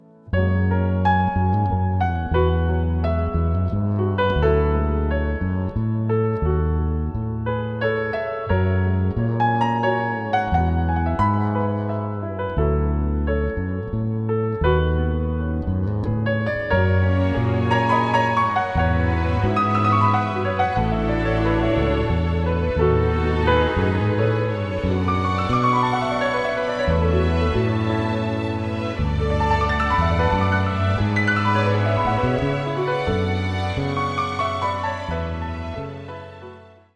a sultry and suspenseful score